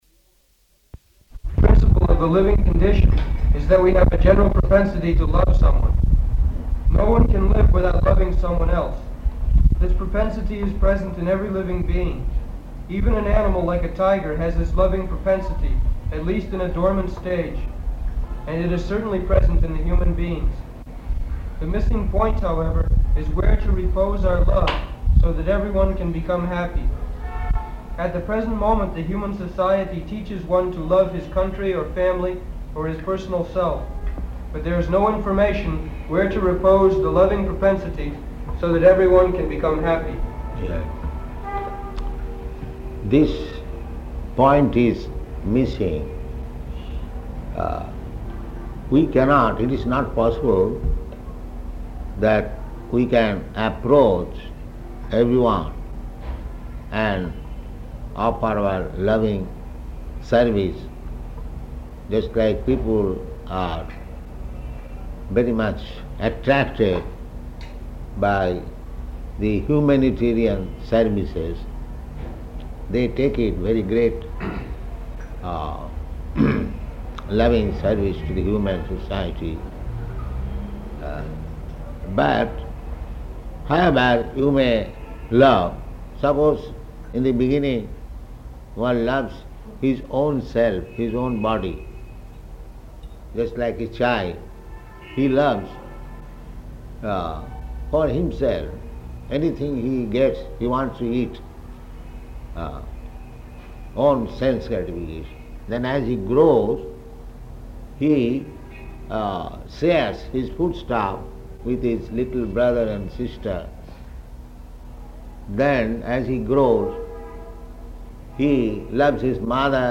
Location: Bombay
[Distorted audio]